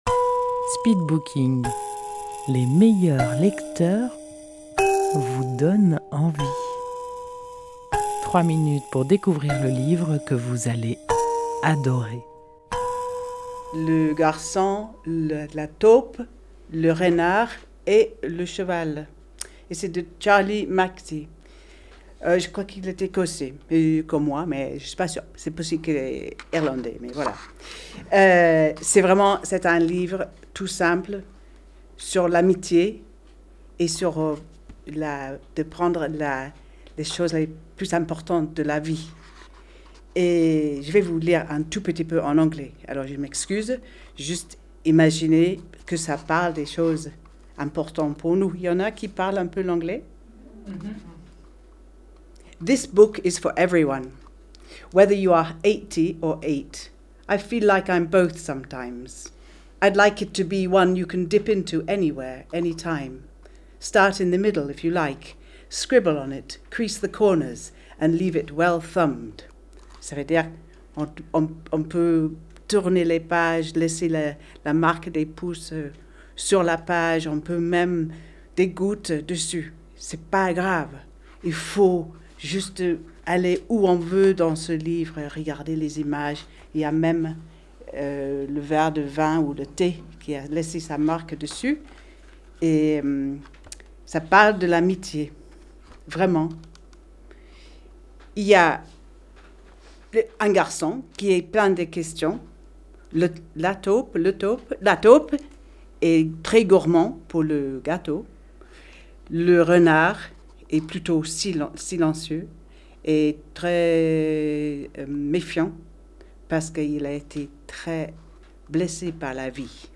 Speed booking Rendez-vous au speed booking : les meilleurs lecteurs vous font partager leur passion pour un livre en 3 minutes chrono et en public. Enregistré en public au salon de thé Si le cœur vous en dit à Dieulefit.